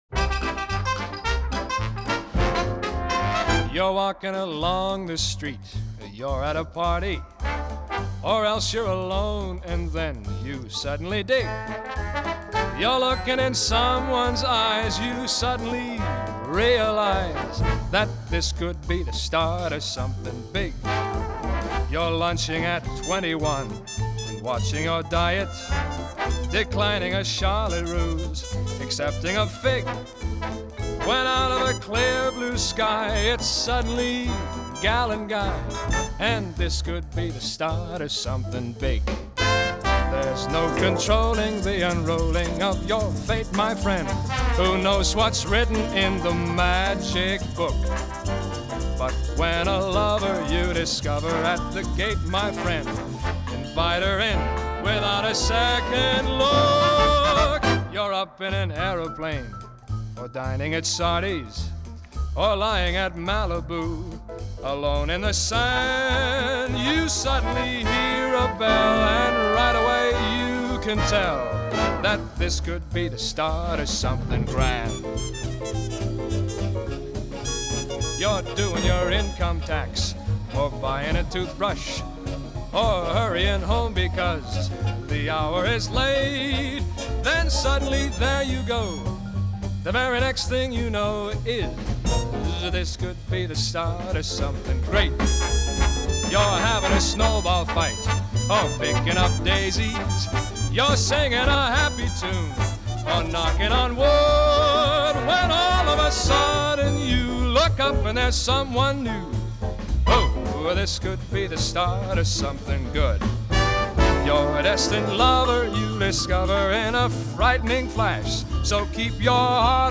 1956   Genre: Pop   Artist